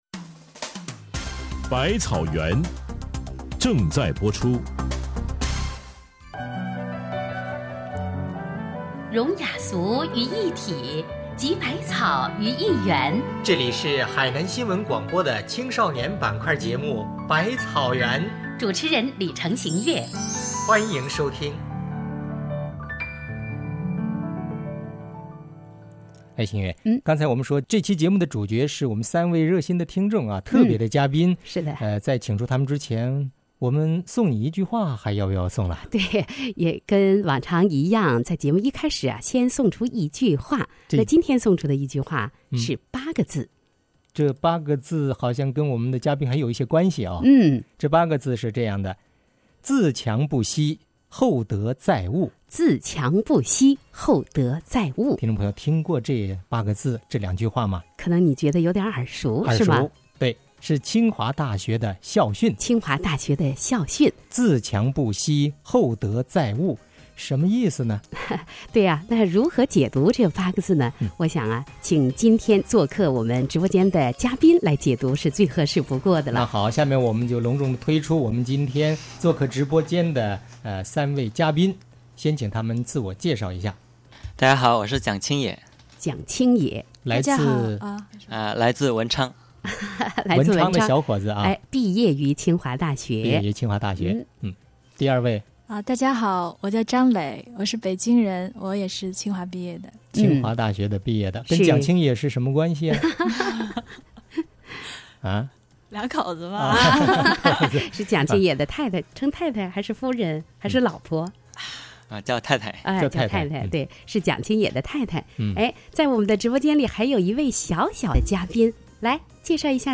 《百草园》访谈